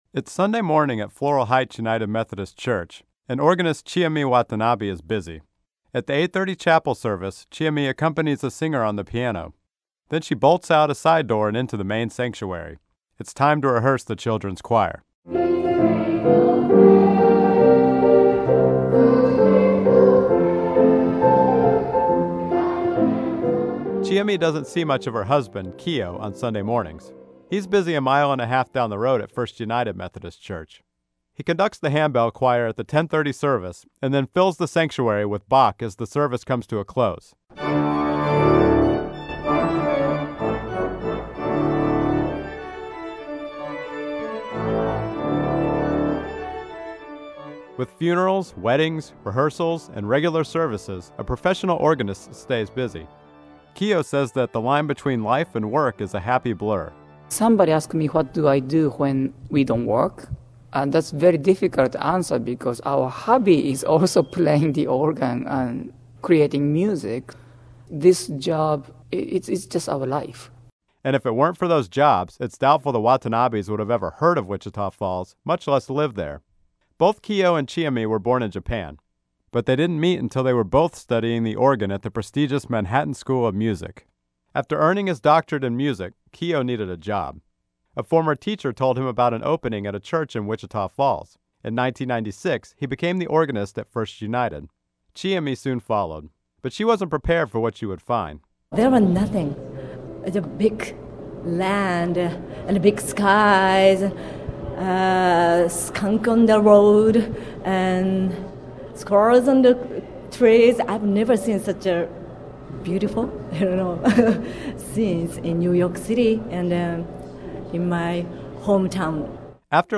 KERA radio story: